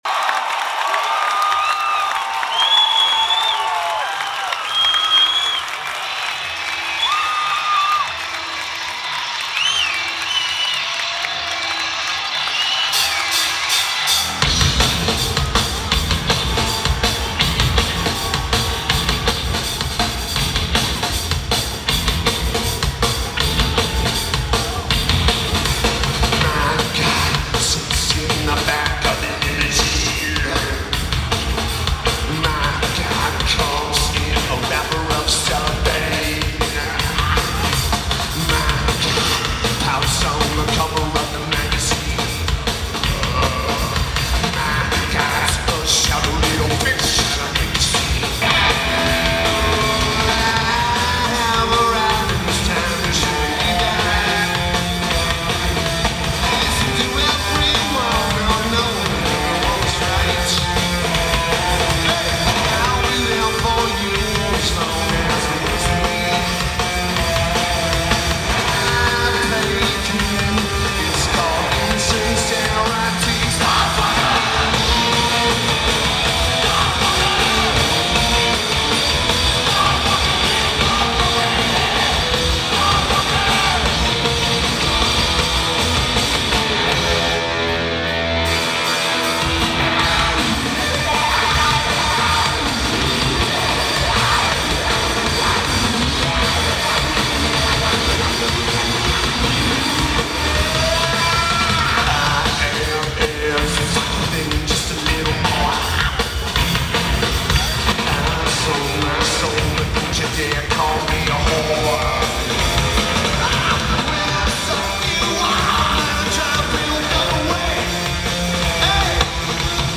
Mullins Center
Keyboards/Bass/Backing Vocals
Vocals/Guitar/Keyboards
Lineage: Audio - AUD (Sony ECM-DS70P + Sony MZ-RH10)
a bit heavy on the treble.